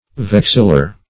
Vexillar \Vex"il*lar\, Vexillary \Vex"il*la*ry\, [Cf. F.